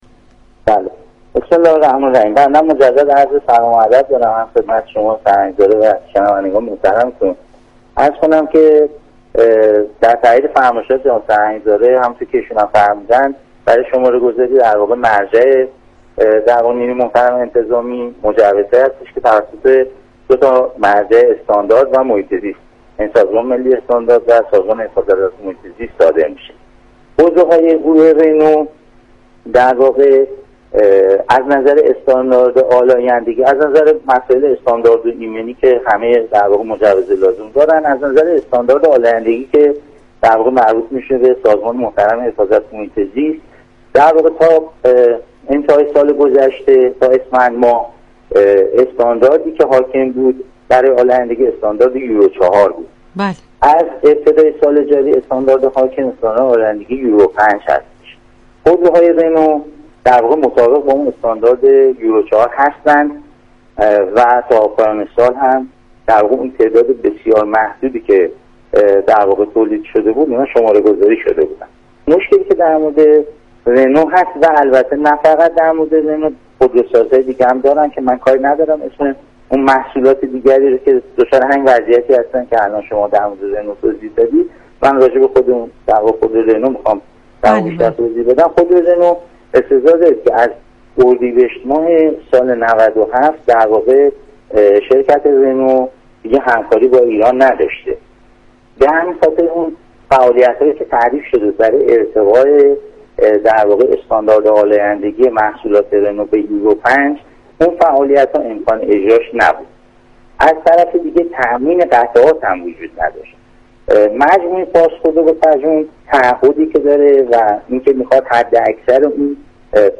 گزارشگر